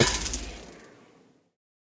Minecraft Version Minecraft Version latest Latest Release | Latest Snapshot latest / assets / minecraft / sounds / block / trial_spawner / break2.ogg Compare With Compare With Latest Release | Latest Snapshot